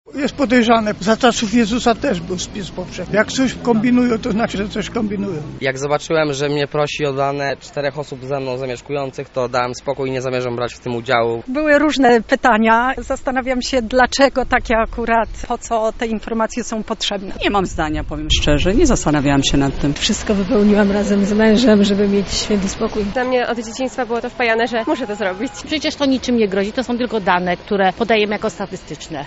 Sonda Spis Powszechny